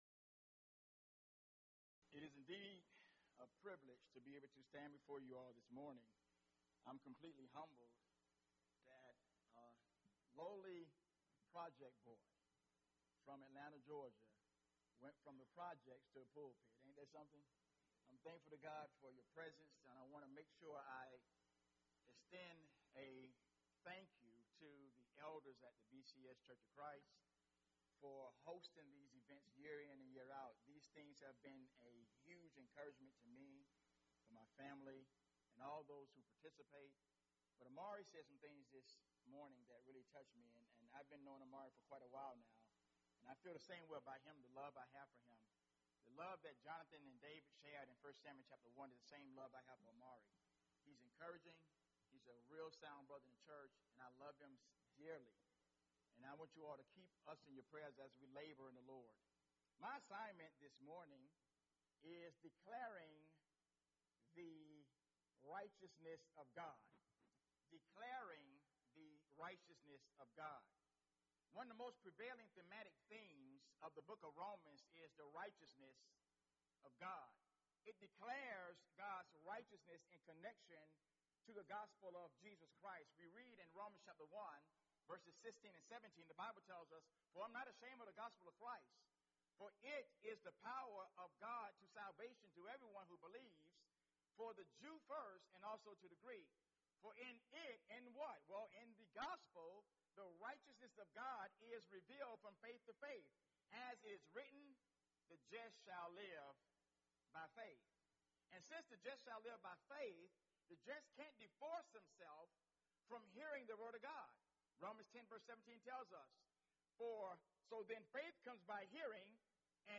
Event: 4th Annual Men's Development Conference
lecture